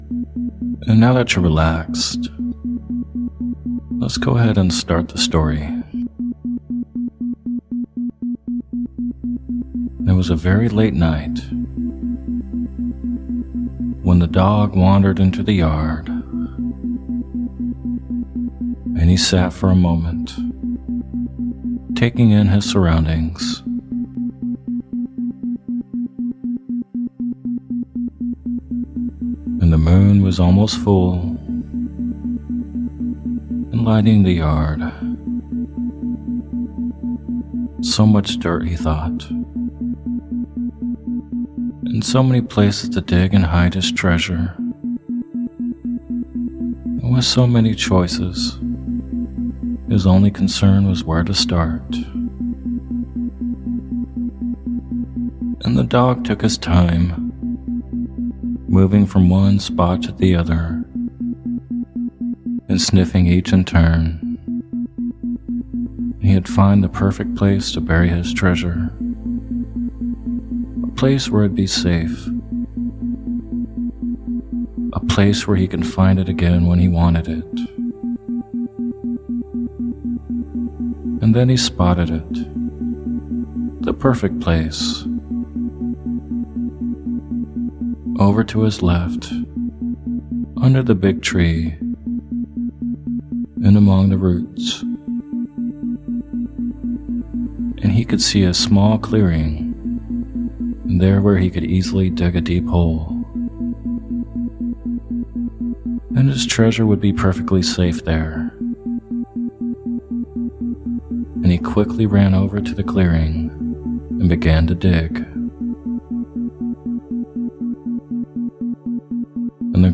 Story Based Meditation "Finding Lost Treasure" With Isochronic Tones